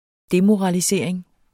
Udtale [ ˈdemoʁɑliˌseɐ̯ˀeŋ ]